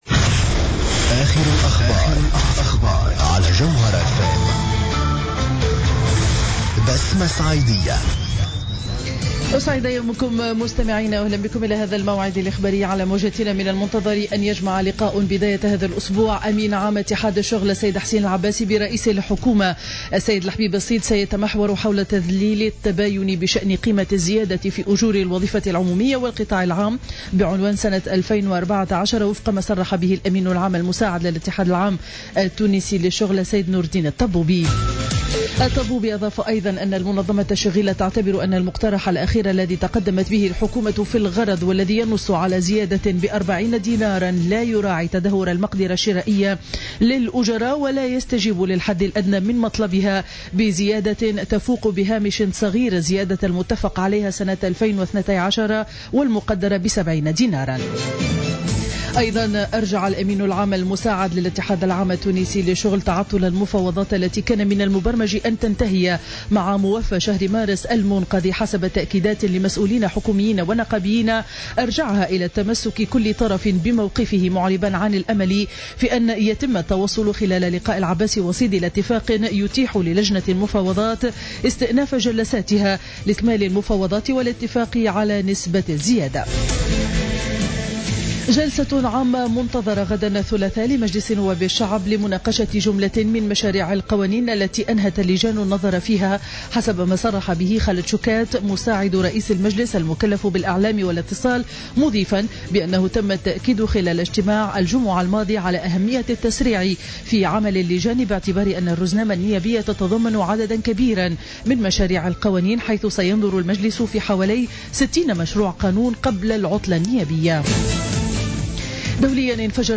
نشرة أخبار السابعة صباحا ليوم الاثنين 13 أفريل 2015